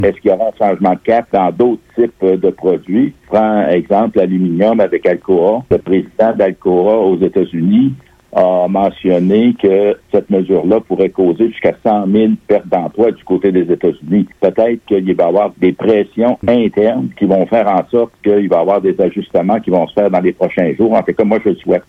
En entrevue à Radio-Beauce, le député de Beauce-Nord, Luc Provençal, affirme que les carnets de commandes plusieurs entreprises sont en suspens. Le député Provençal espère qu’il y aura un pas de recul de la part du président américain pour éviter une catastrophe au niveau des emplois.